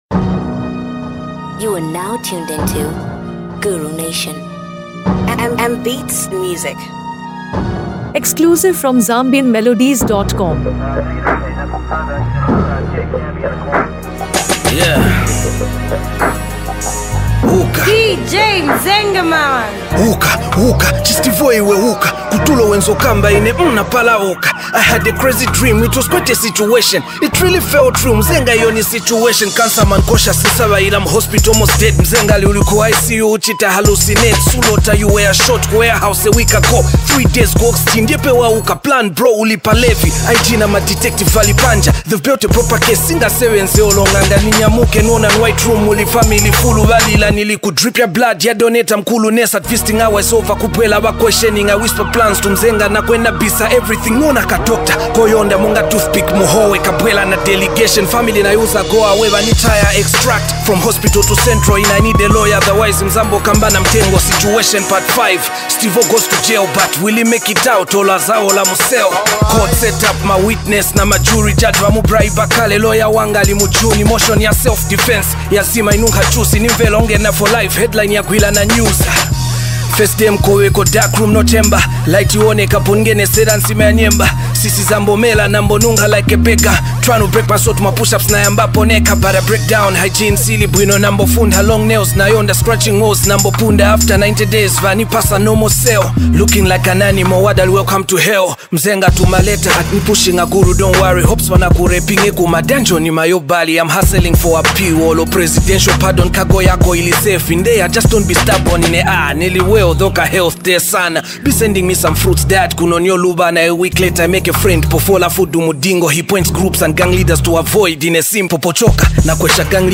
The song is delivered in a raw, unapologetic tone
conscious rap